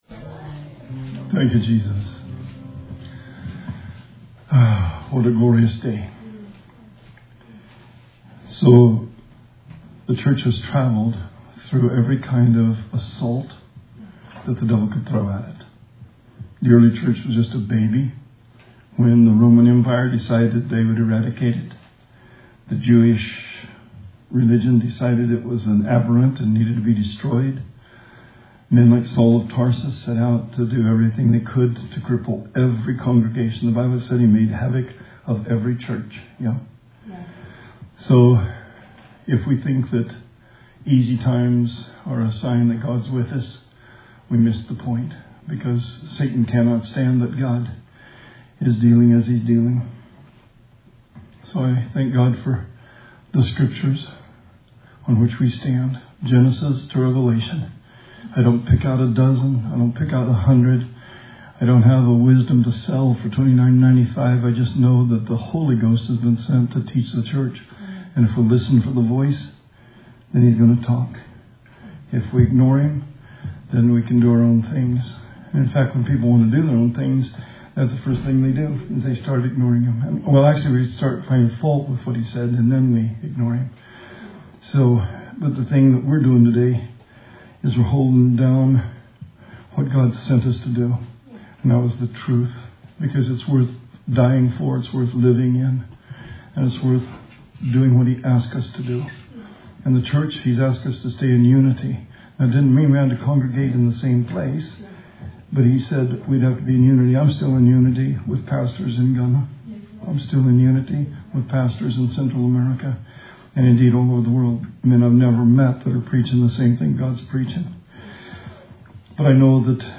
Sermon 4/12/20